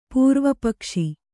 ♪ pūrvapakshi